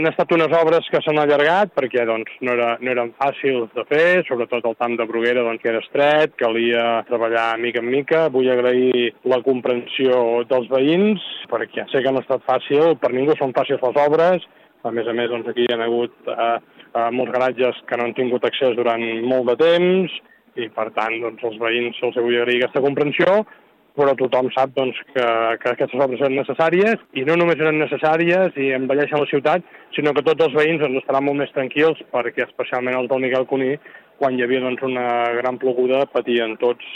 L’alcalde ha tingut paraules d’agraiment pel veïnatge que ha esperat pacient la finalització de les obres.